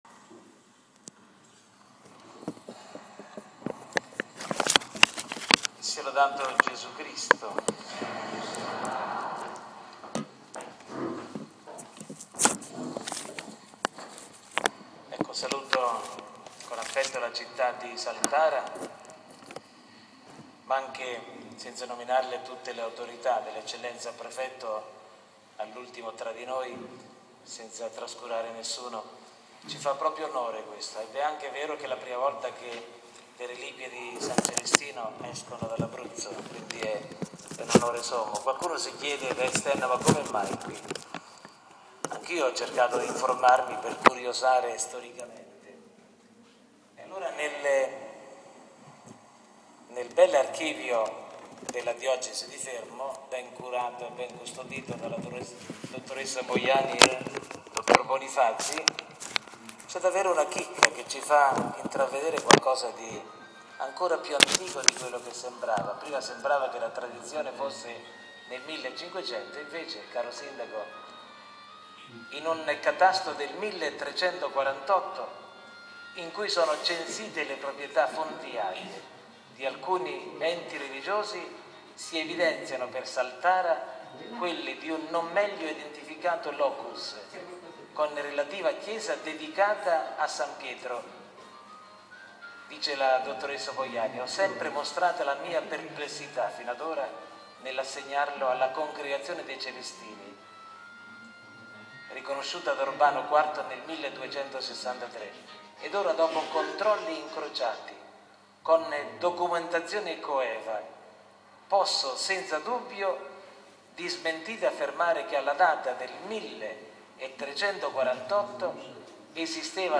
Con queste parole il Vescovo Armando Trasarti ha aperto solennemente la Settimana Giubilare Celestiniana a Saltara venerdì 10 settembre.
Omelia-arrivo-Pier-Celestino.mp3